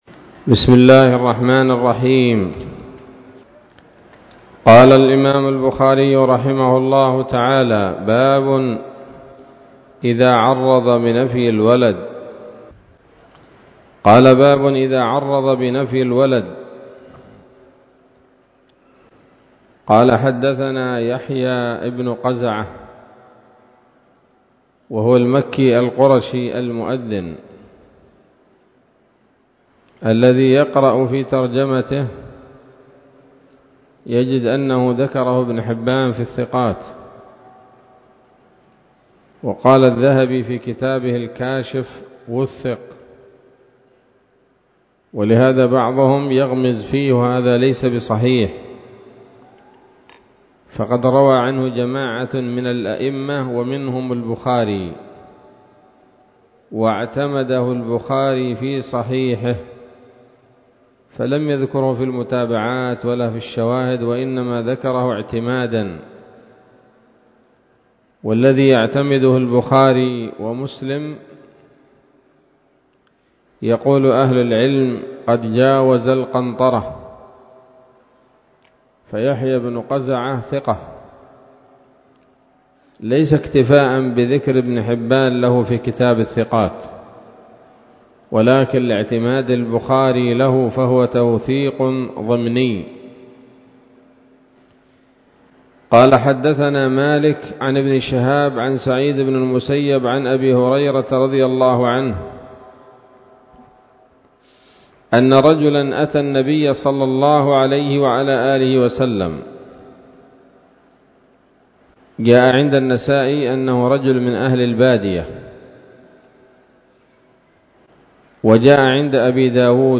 الدرس الحادي والعشرون من كتاب الطلاق من صحيح الإمام البخاري